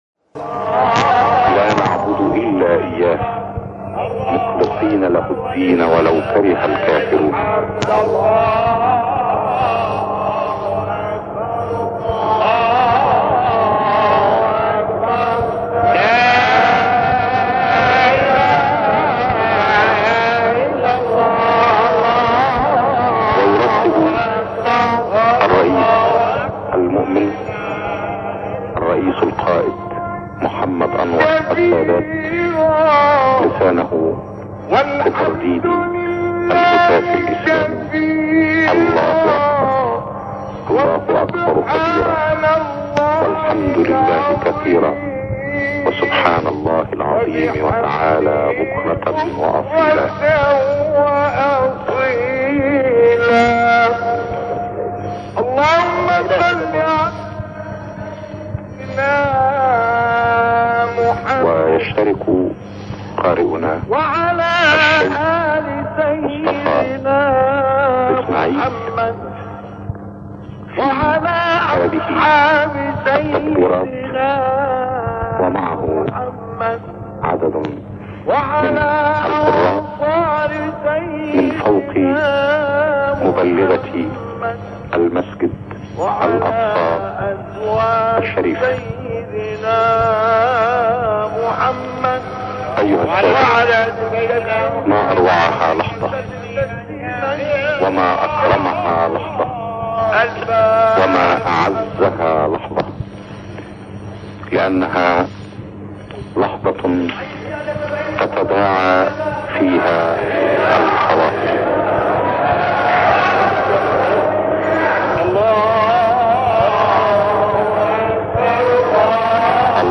تکبیرات عید مسجدالاقصی سال1977م با صدای مصطفی اسماعیل
برای نخستین بار فایلی شنیده نشده از قاری بزرگ اسلام مصطفی اسماعیل با عنوان تکبیرات عید مسجدالاقصی سال1977م منتشر می شود